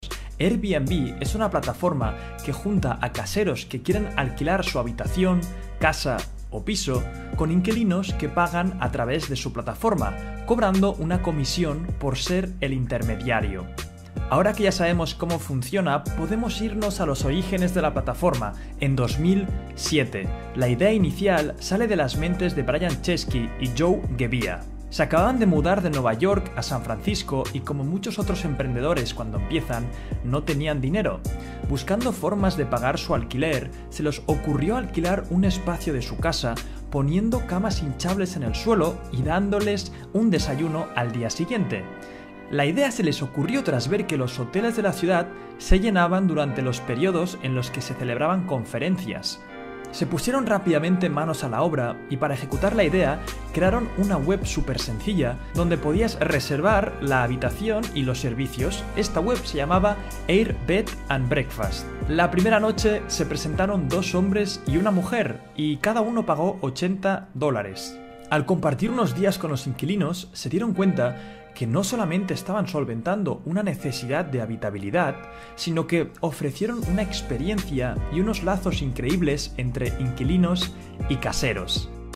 ESPAÑOL DE ESPAÑA